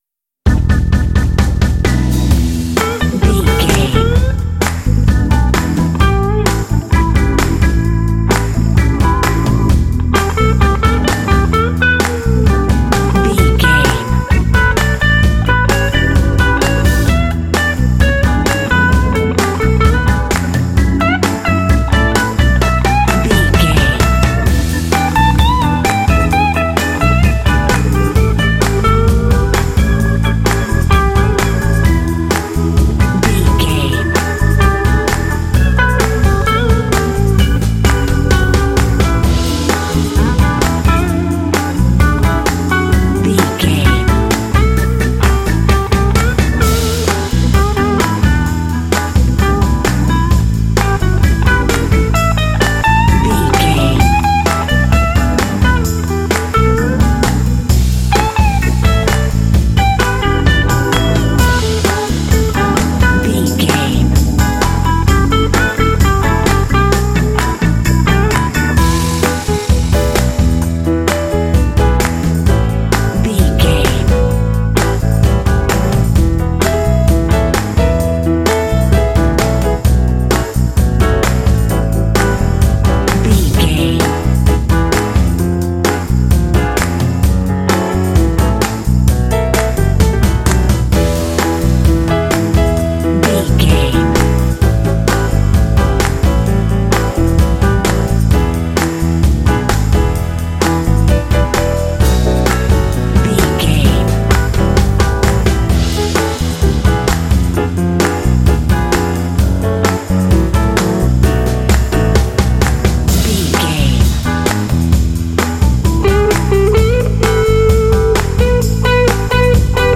This groovy track is ideal for action and sports games.
Aeolian/Minor
lively
energetic
fun
groovy
electric guitar
electric organ
bass guitar
drums
piano
indie